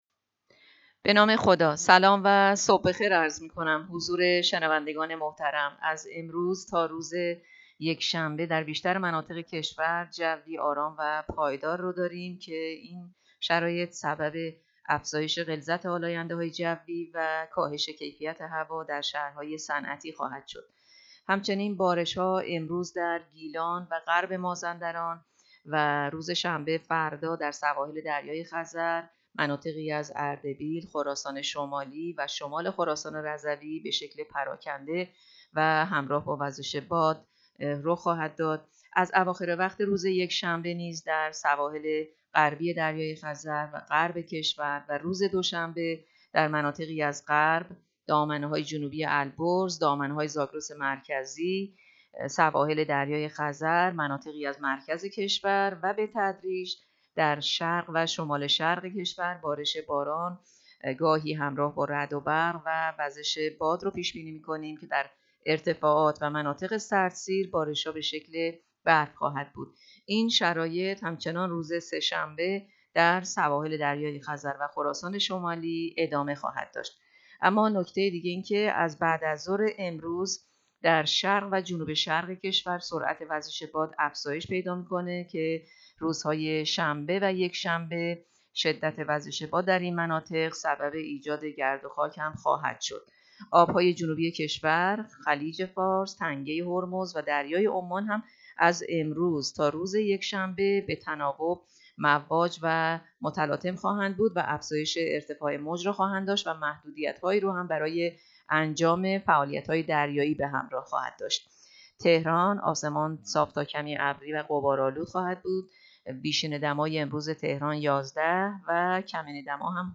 گزارش رادیو اینترنتی پایگاه‌ خبری از آخرین وضعیت آب‌وهوای ۱۶ آذر؛